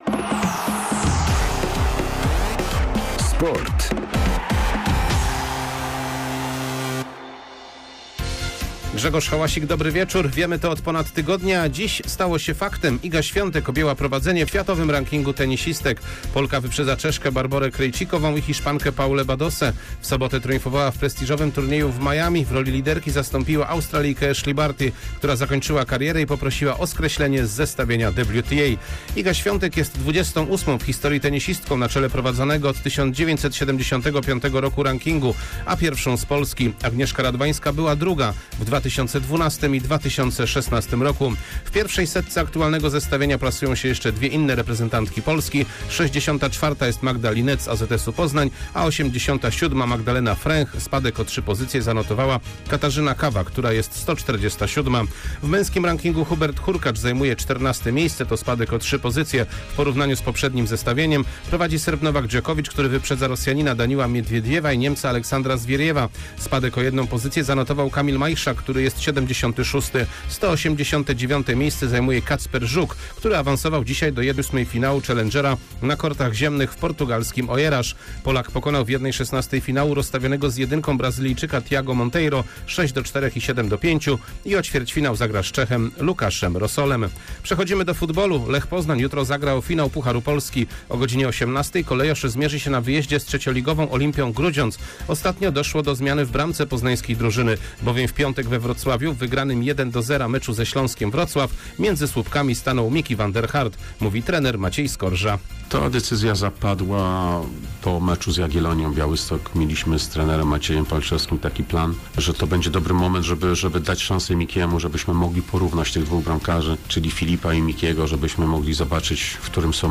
04.04.2022 SERWIS SPORTOWY GODZ. 19:05